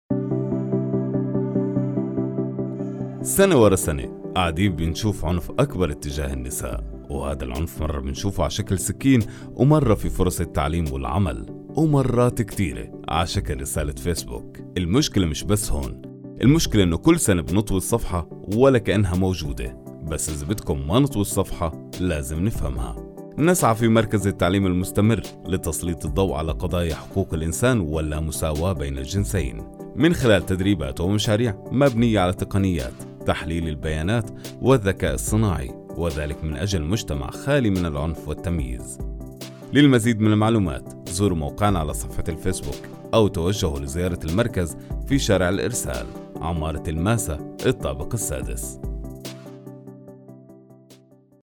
Radio Spot 3